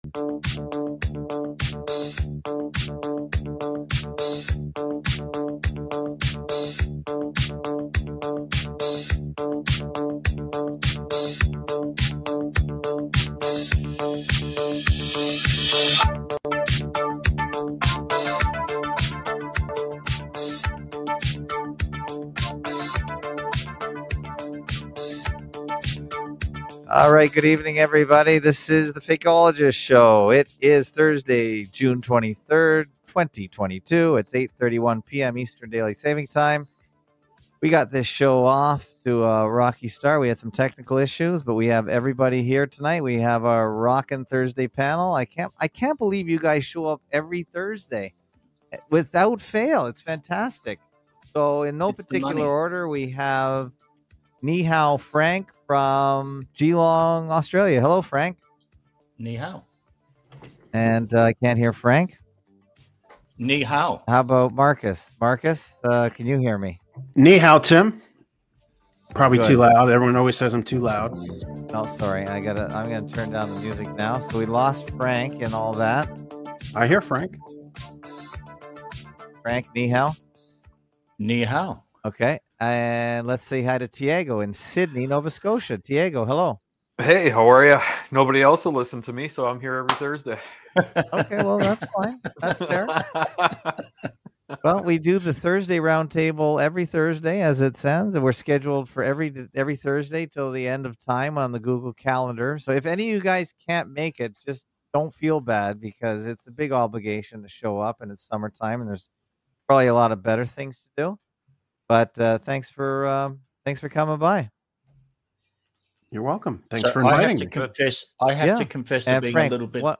Permanent Link URL Friendly (SEO) Current Time (SEO) Category: Live Stream You must login to be able to comment on videos Login Load More